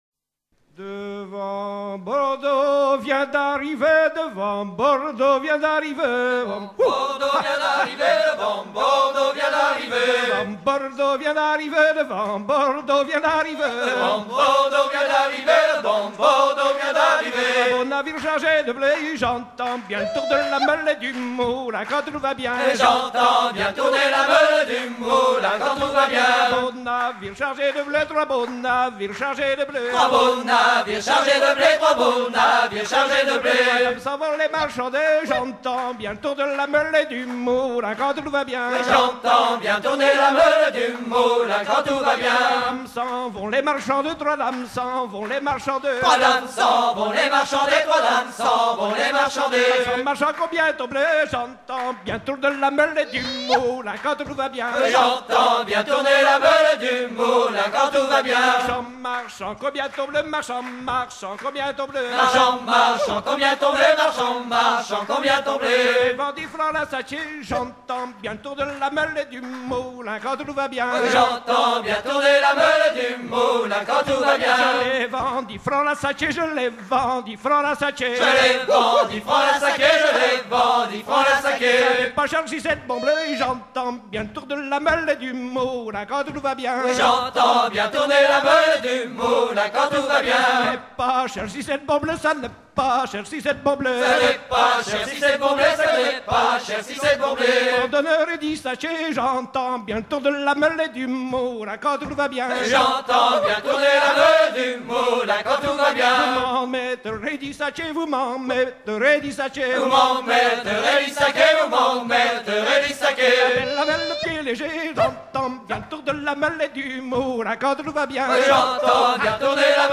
danse : laridé, ridée
Pièce musicale éditée